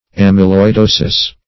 amyloidosis \am`y*loi*do"sis\ ([a^]m`[i^]*loi*d[=o]"s[i^]s), n.